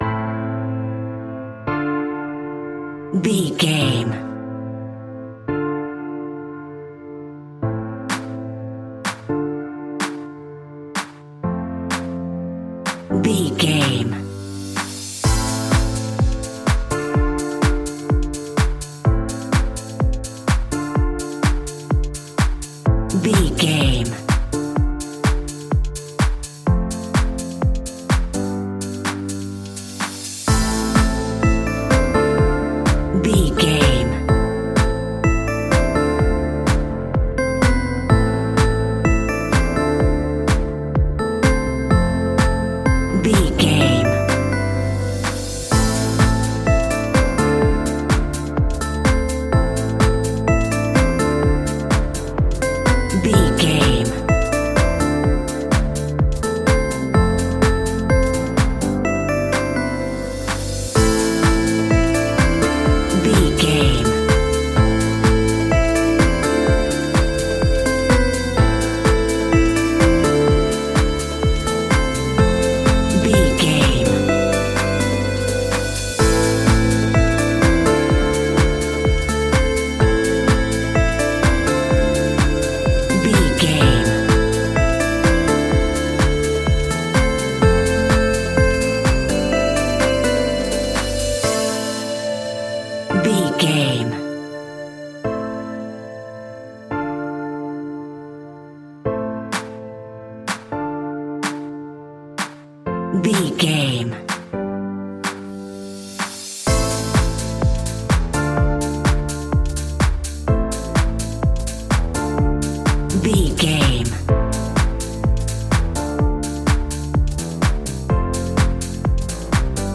A great piece of royalty free music
Aeolian/Minor
groovy
uplifting
futuristic
happy
drum machine
synthesiser
bass guitar
funky house
electro
disco
nu disco
upbeat
funky guitar
synth bass
horns
uptempo